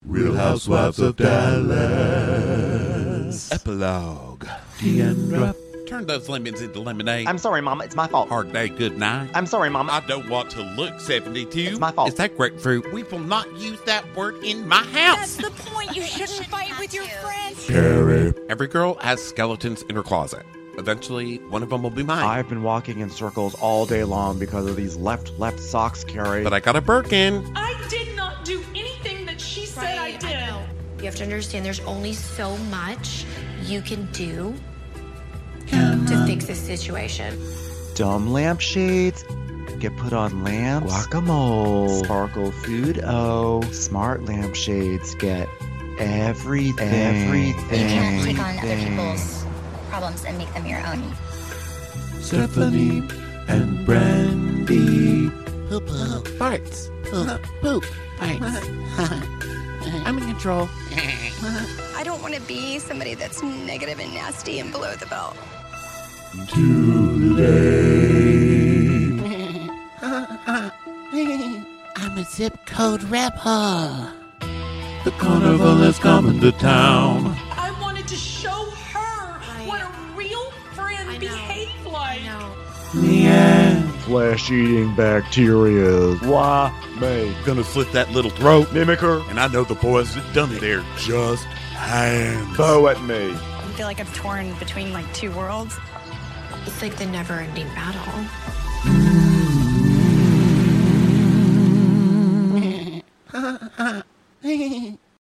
RHOD: Musical Epilogue